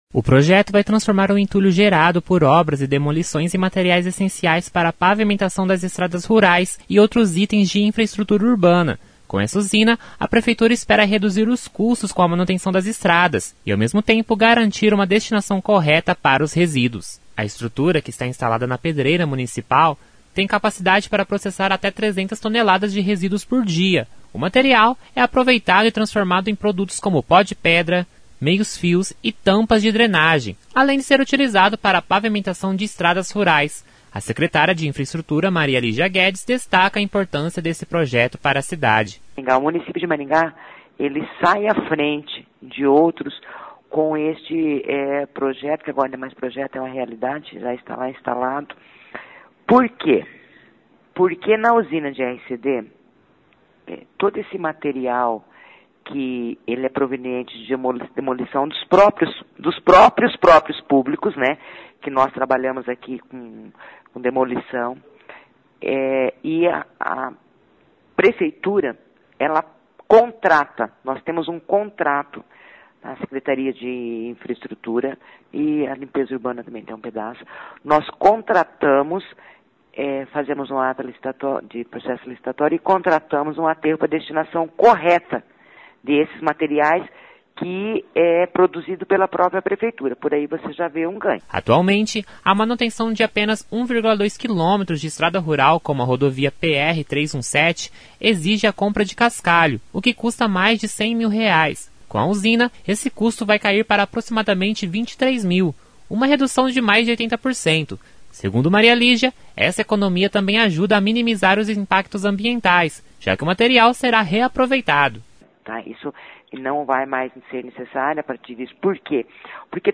A secretária de Infraestrutura, Maria Lígia Guedes, destaca a importância desse projeto para a cidade.